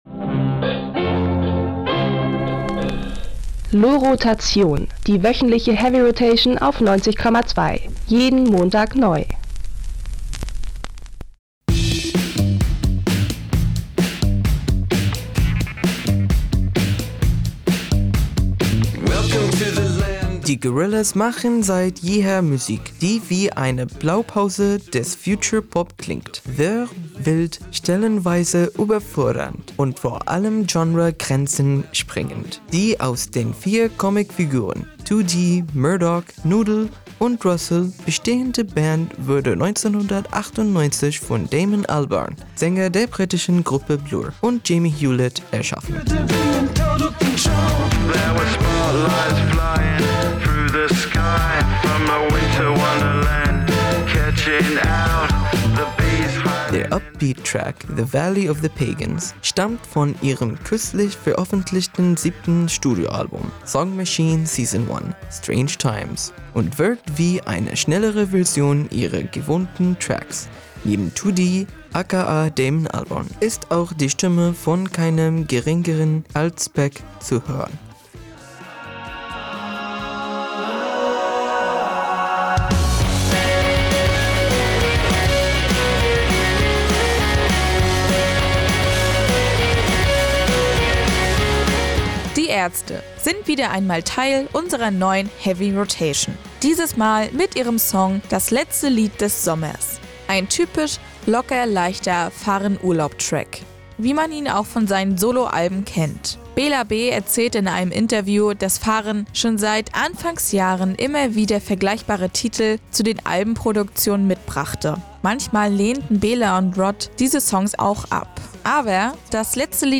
Souliger Indie-Pop aus London